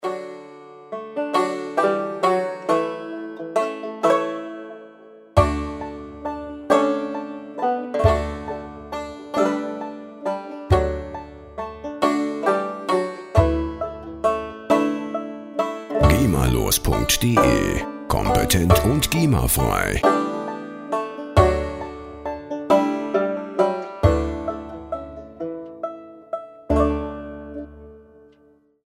Der Klang der Musikinstrumente
Instrument: Banjo
Tempo: 135 bpm